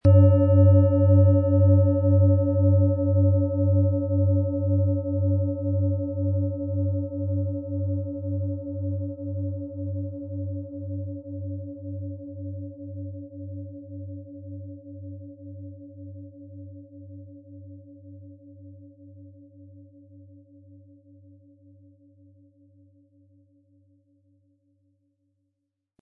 Tageston
Schon beim ersten Anspielen entfaltet sie ihren unverwechselbaren Klang: Der tiefe, ruhige Grundton ist exakt auf den Tageston abgestimmt.
• Mittlerer Ton – Mars: Ein vitalisierender Klang, der das Selbstbewusstsein stärkt und frische Energie schenkt.
• Höchster Ton – Pluto: Die leise Einladung zur Transformation.
Das Klangbeispiel spielt Ihnen den Tonder hier angebotenen Klangschale ab.